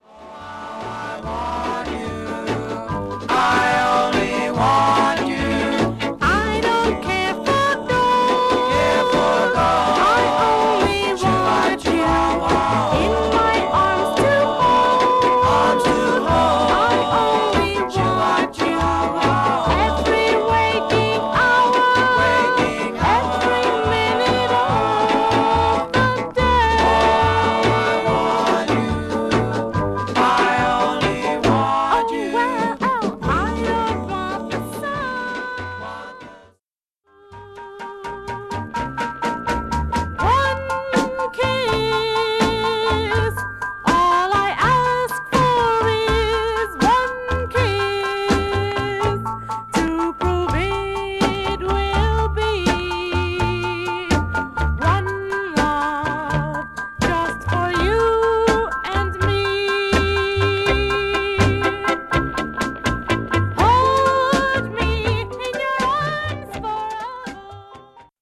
Genre: Doowop/Vocal Groups
Doo-wop Teener, uptempo b/w ballad